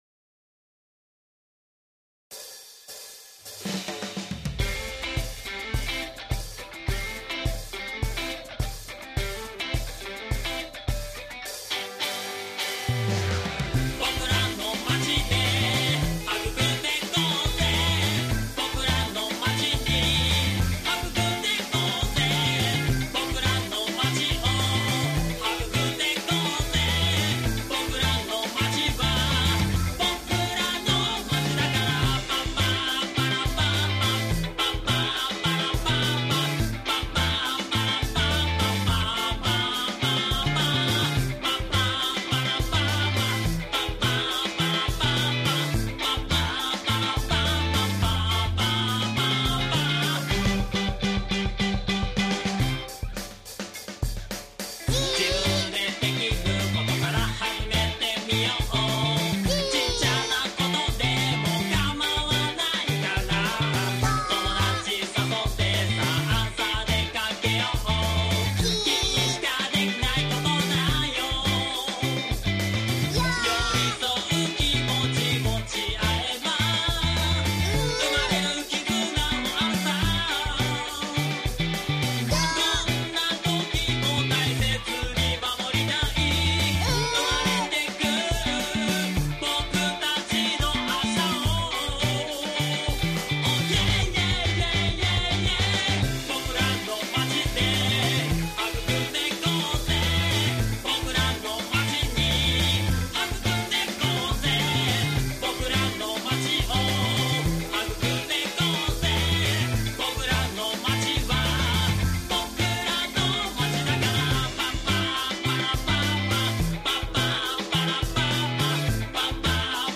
地域活性化ロックンロールバンド